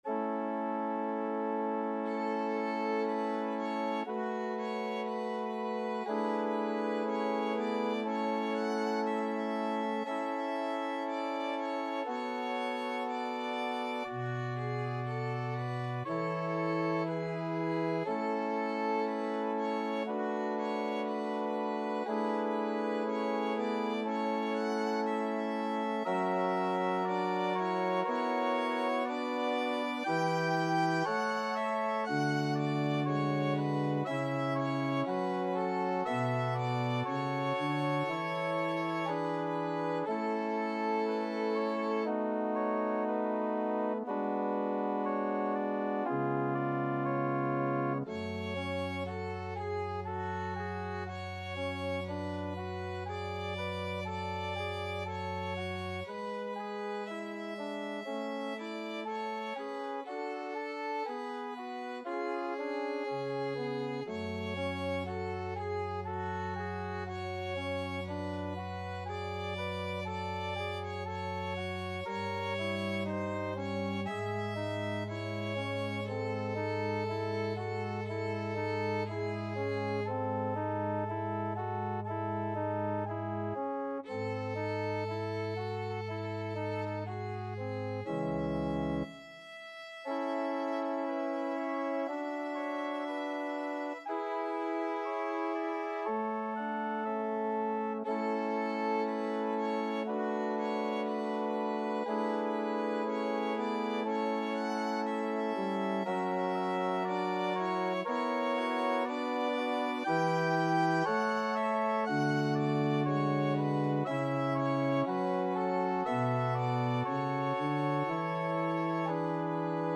2/4 (View more 2/4 Music)
Classical (View more Classical Violin Music)